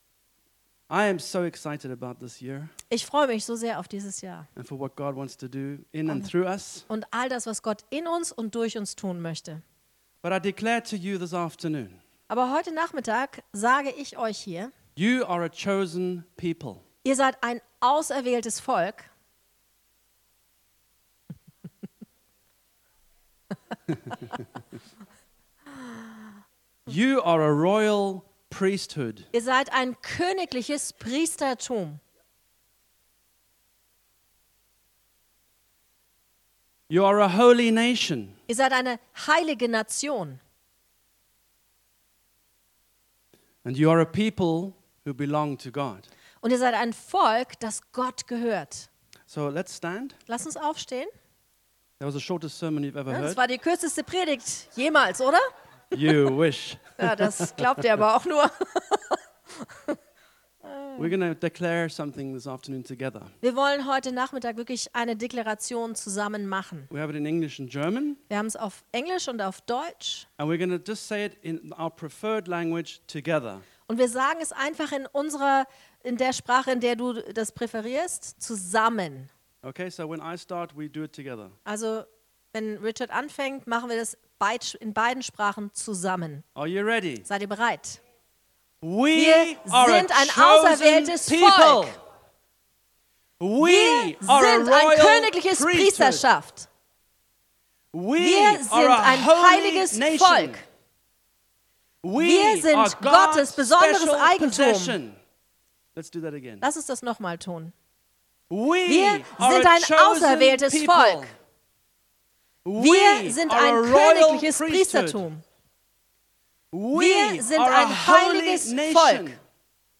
Sermons from KLF